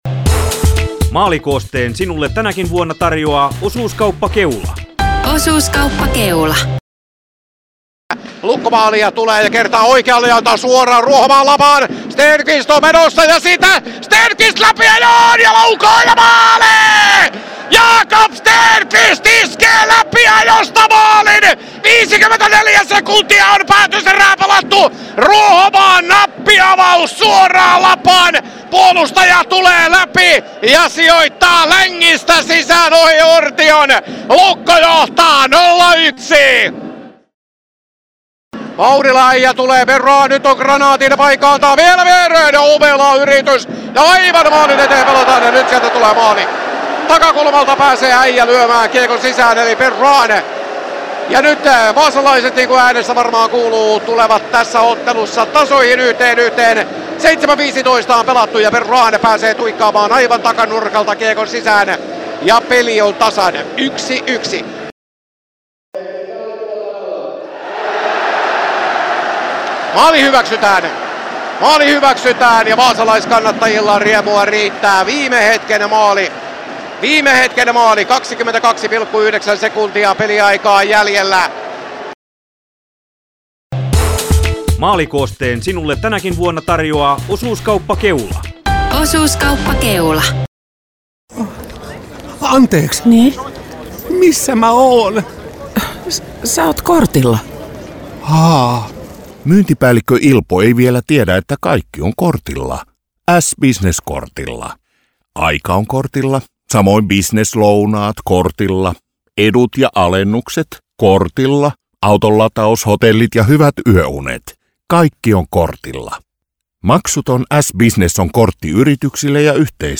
Maalikooste Sport-Lukko 1.11.2025